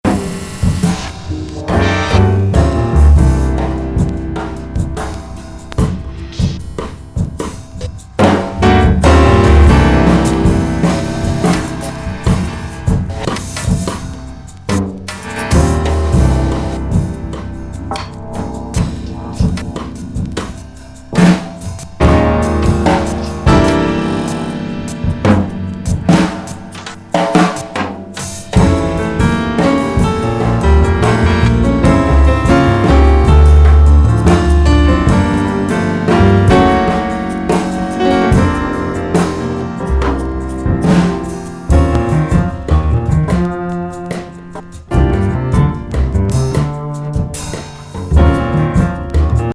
リングした生音と融合することで構築された、唯一無二的夢見心地な世界観。
楽曲性のあるハウス・トラックとしても楽しめる3枚組!